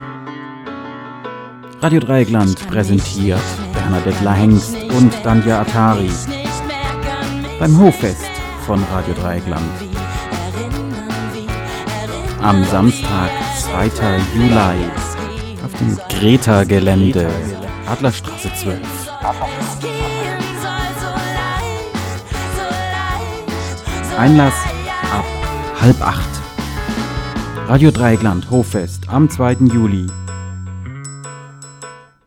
Jingle fürs Hoffest am 2.